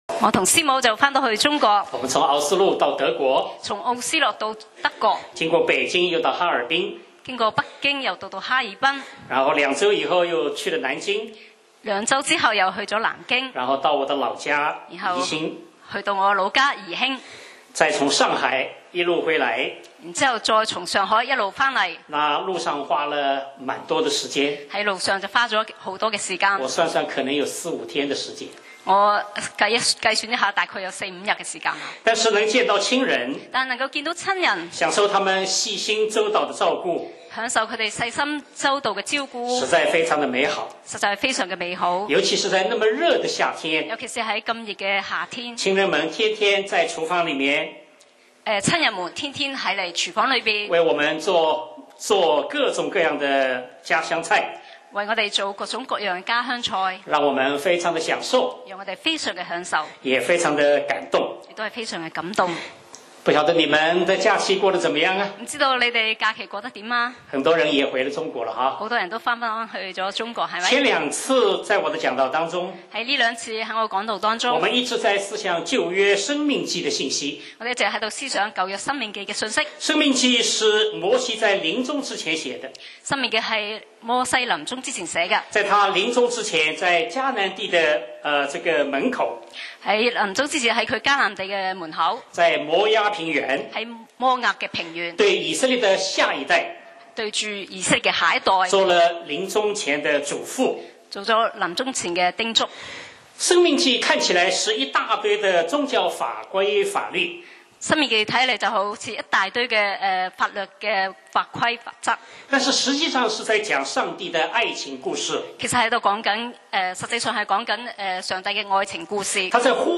講道 Sermon 題目 Topic：爱的生活 經文 Verses：申18：9-13，26：16-19. 9你到了耶和华你神所赐之地，那些国民所行可憎恶的事，你不可学着行。